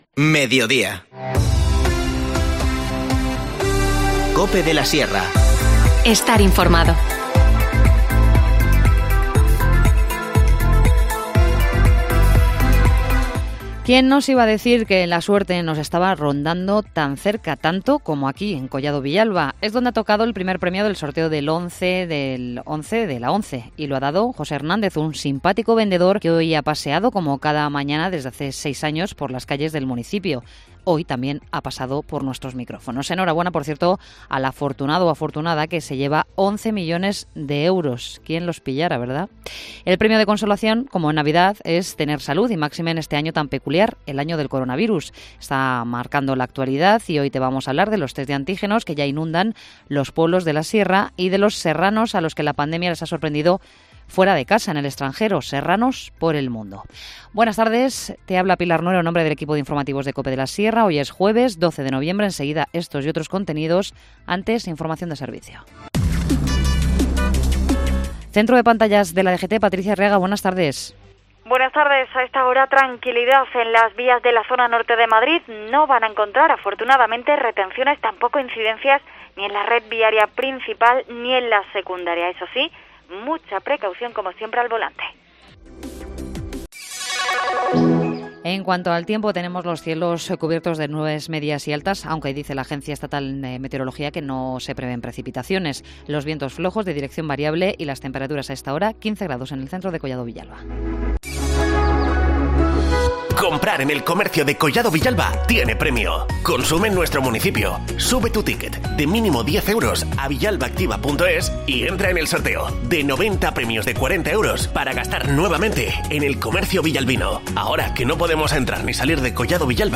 Informativo Mediodía 12 noviembre